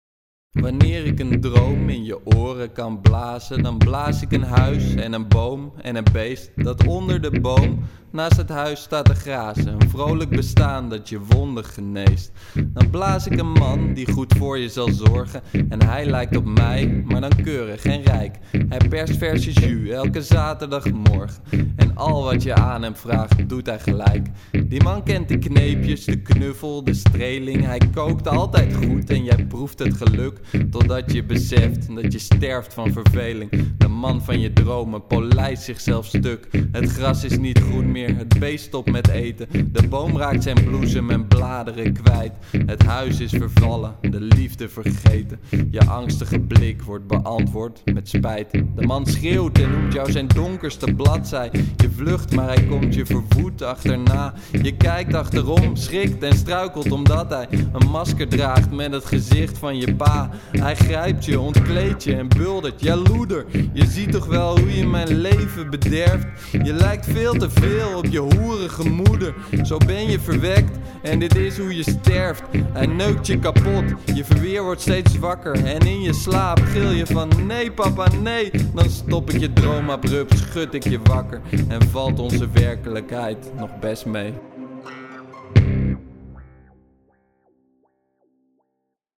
De GVR (blues)